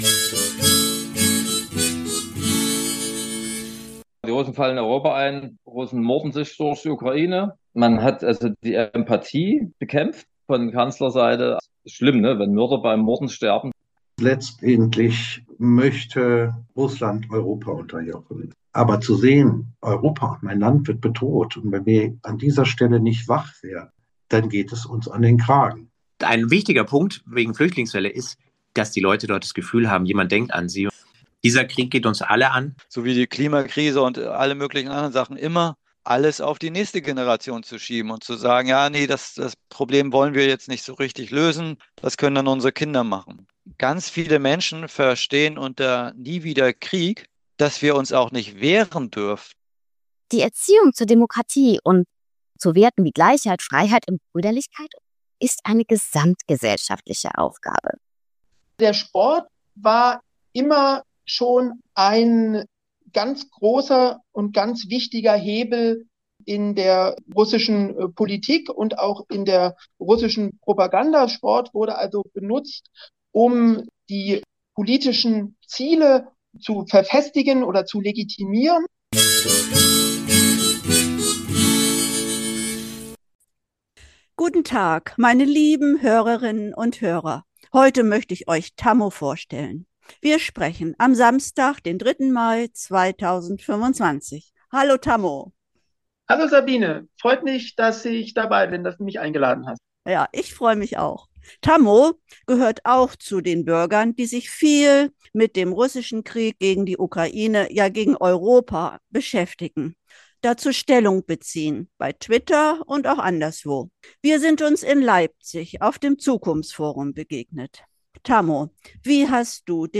Bürger tun was dazu: Interview